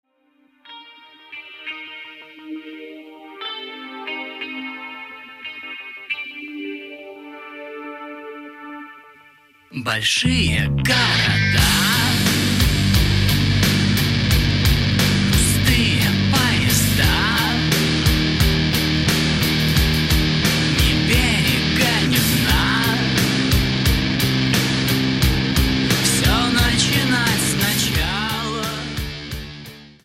грустные
post-punk
классика русского рока